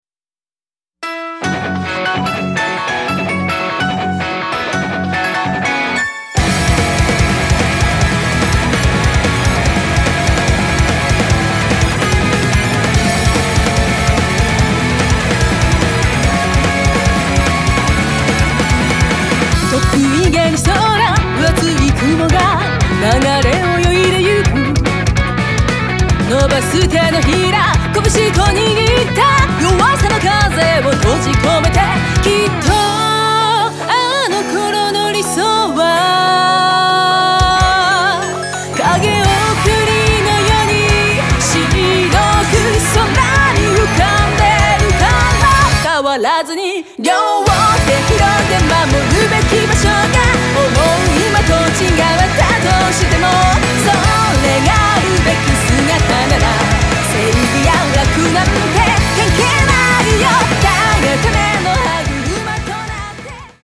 クロスフェードデモ
色とりどりのアレンジ満開の東方ヴォーカルポップ＆ロックアレンジ！